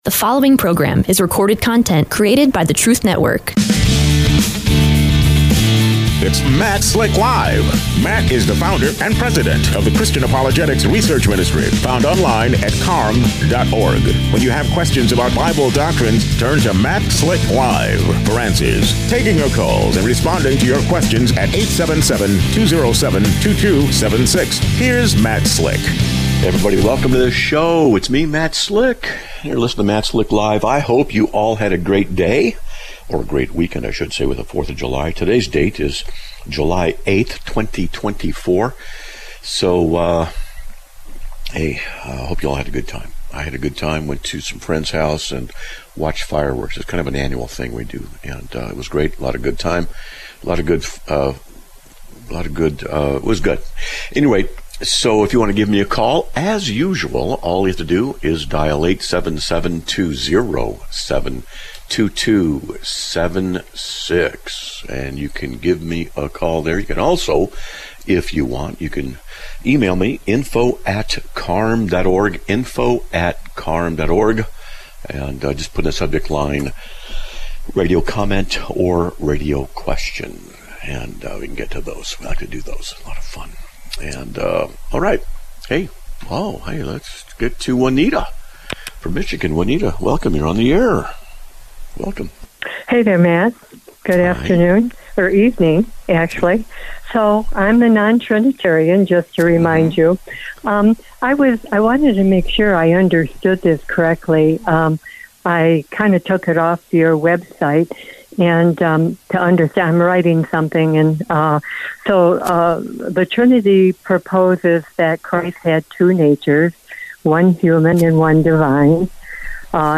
A Caller Comments about a Previous Caller, Talks About Current Happenings in Israel